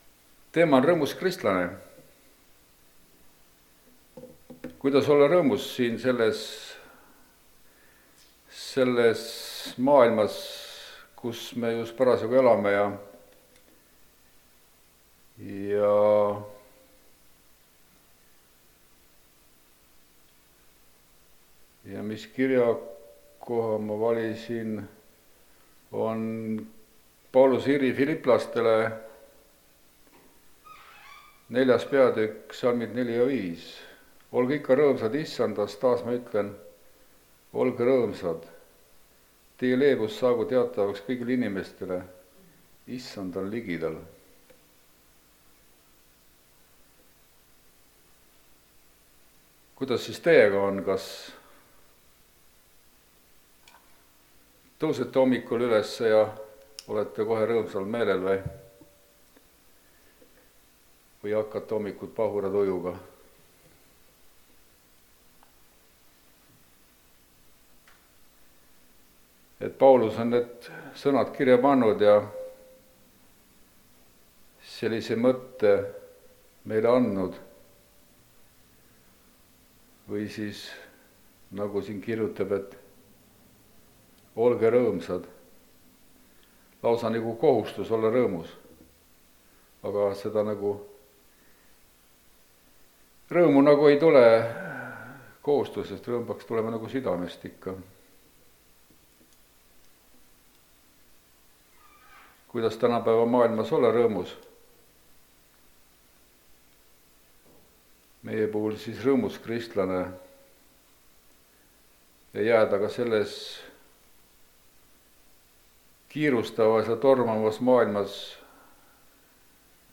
Rõõmus kristlane (Rakveres)
Jutlused